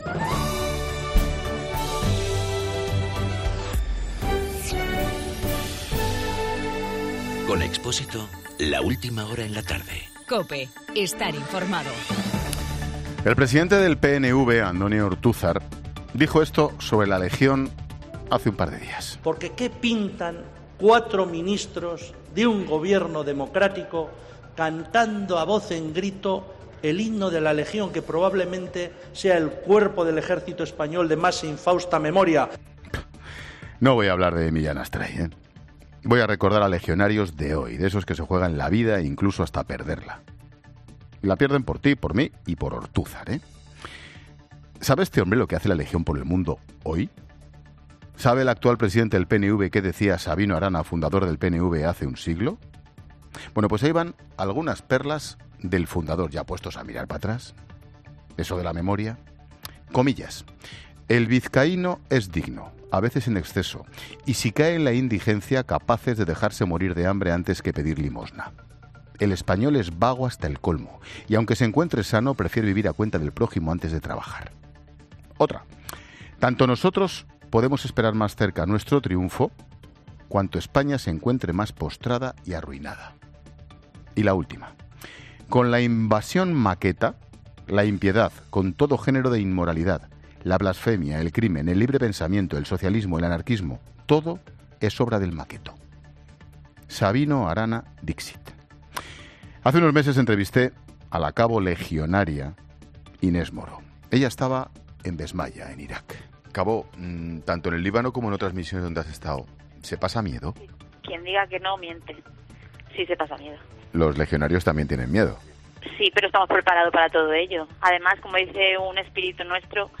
Monólogo de Expósito
Comentario de Ángel Expósito sobre las declaraciones del presidente del PNV, Antonio Ortuzar, sobre la legión.